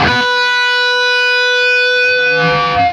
LEAD B 3 CUT.wav